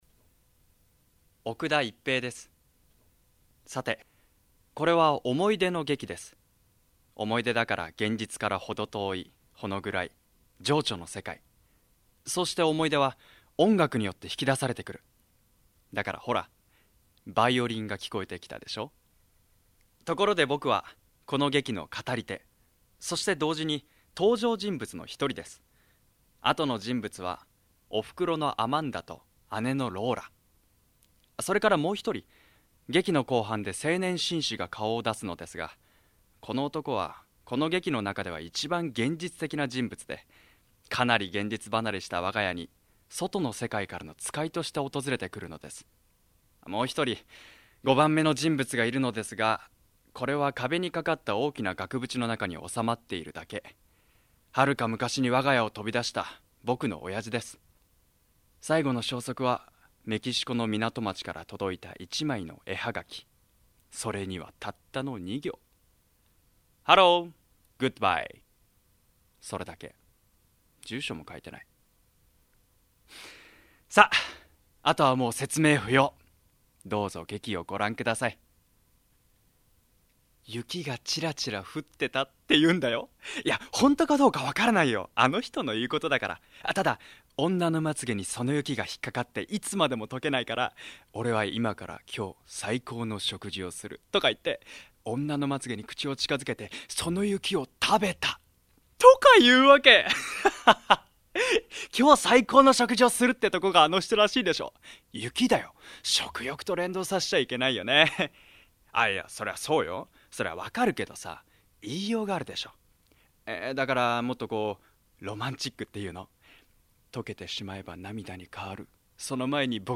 趣味･特技：英会話（日常会話）、大分弁、料理
ボイスサンプル